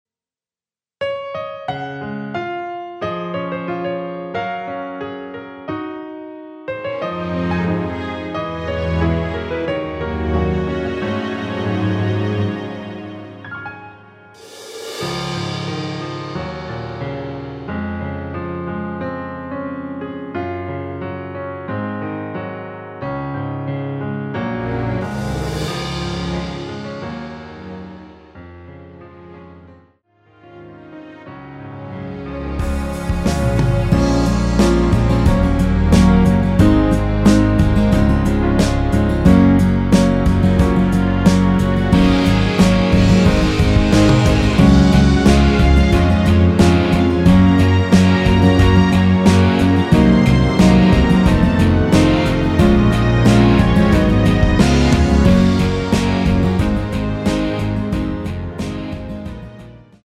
*(-4) MR 입니다.
여성분이 부르실 축가로 좋은곡
C#
앞부분30초, 뒷부분30초씩 편집해서 올려 드리고 있습니다.